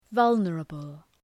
Προφορά
{‘vʌlnərəbəl}